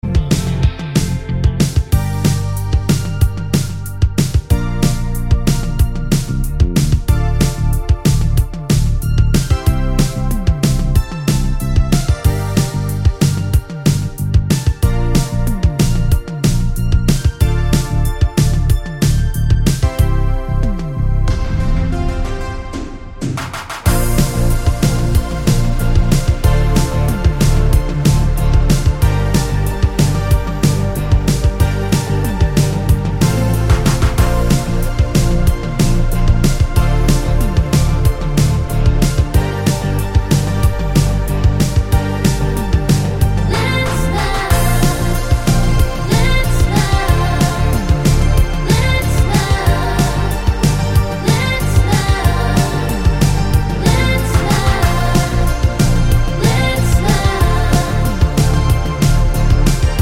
no Backing Vocals Dance 3:30 Buy £1.50